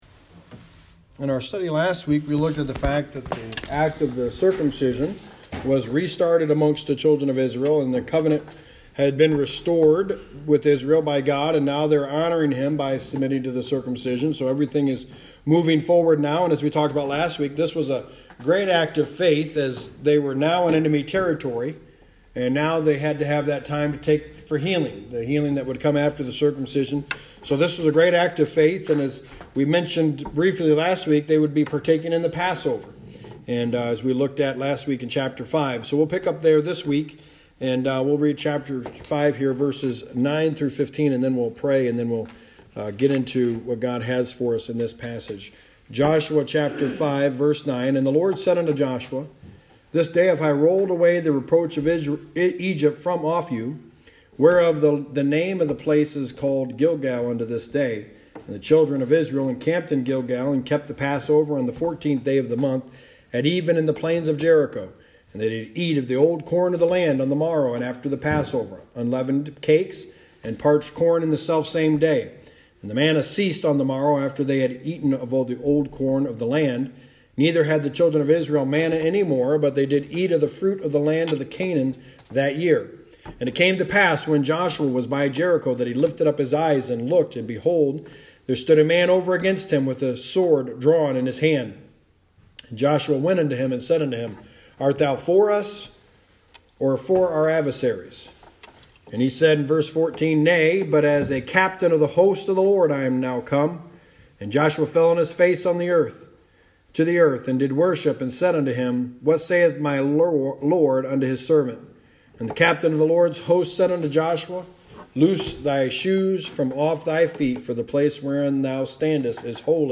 The Book of Joshua: Sermon 17 – Joshua 5:10-15
The Book of Joshua Service Type: Sunday Morning Preacher